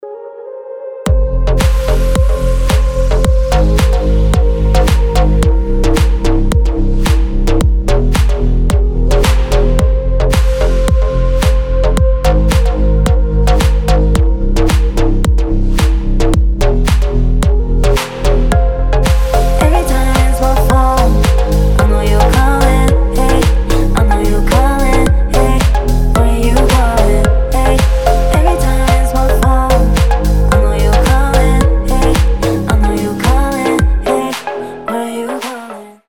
атмосферные
Electronic
EDM
мощные басы
Bass House
красивый женский голос
G-House